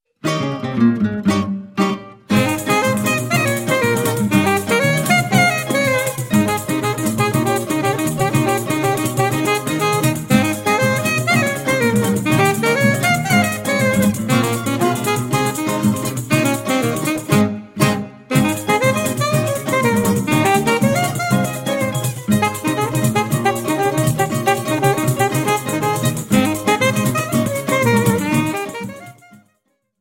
saxofone alto